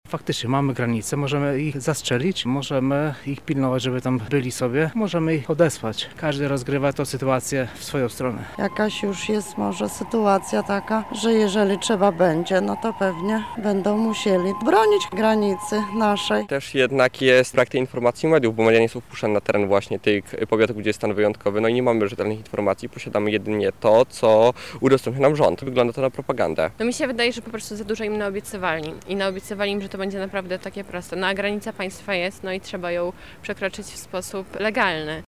Nasza reporterka zapytała, co na temat sytuacji na granicy sądzą mieszkańcy Lublina:
sonda